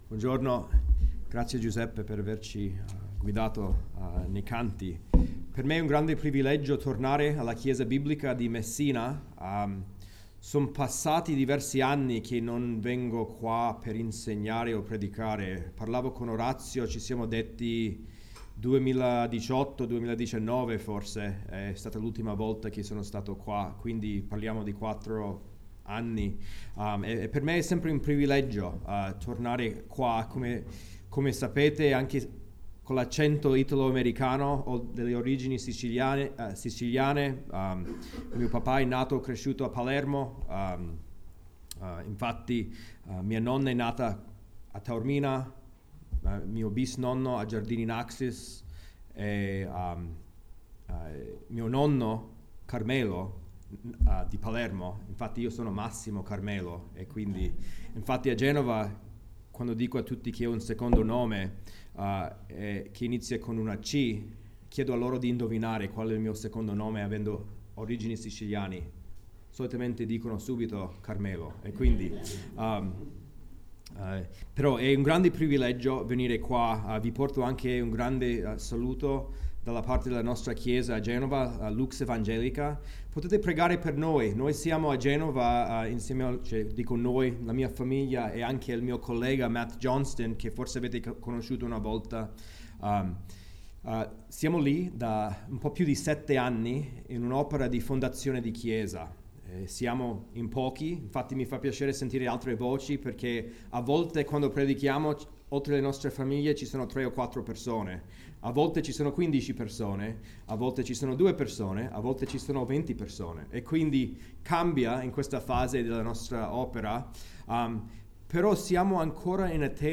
Mag 07, 2023 Tutta la terra è piena della sua gloria MP3 Note Sermoni in questa serie Tutta la terra è piena della sua gloria.